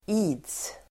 Ladda ner uttalet
Uttal: [i:ds el. is:]